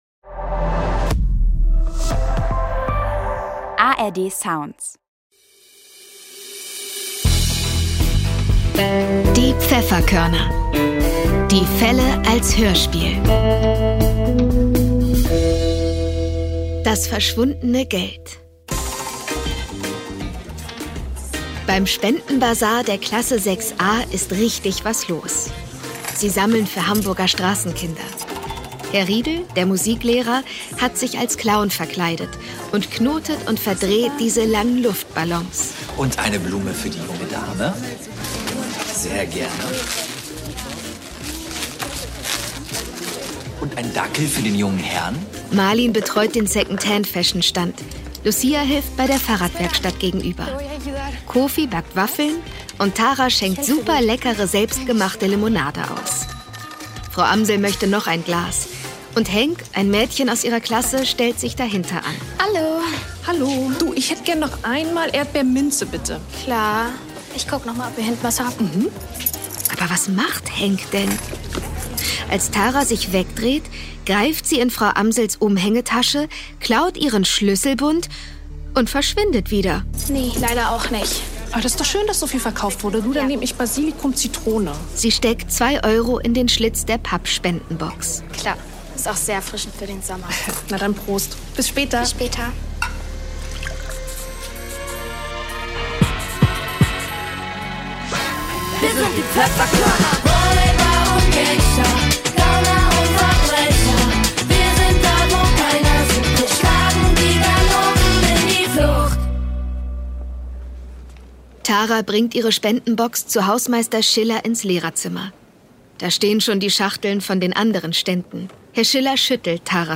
Das verschwundene Geld (17/21) ~ Die Pfefferkörner - Die Fälle als Hörspiel Podcast